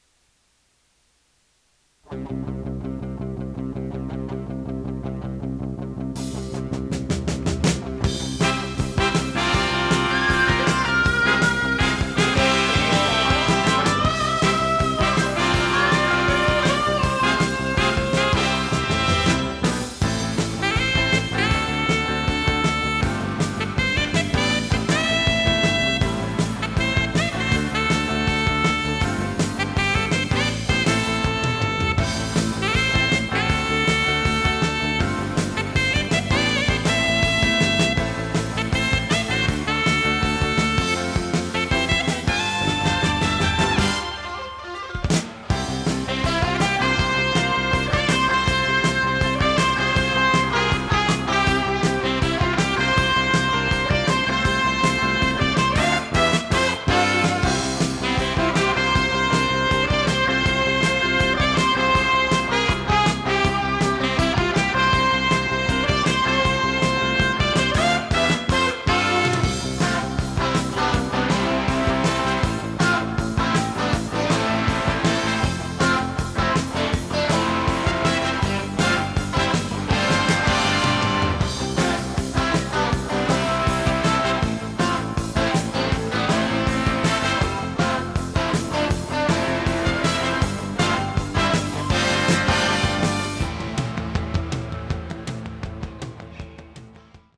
テープ：fox C-60
ノイズリダクションOFF
【フュージョン・ロック】容量26.7MB